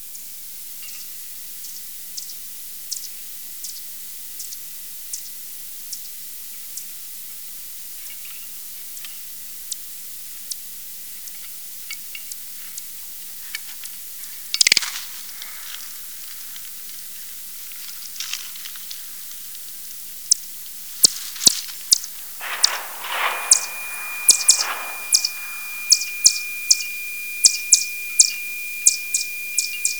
We present here three video and sound sequences of captive mouse-eared bats (Myotis myotis) approaching a live cricket fixed on the surface of a doormat.
The ultrasonic microphones used to record the bats are visible on both sides of the feeding arena.
The noise of the flash reloading is also easily recognizable. Note the reduction in flight speed of the bats when approaching prey as well as the silent/low intensity echolocation calls preceding prey approach.
The accompanying audio sequences show how the bat reduces the intensity of its calls prior to prey capture.
bat1.wav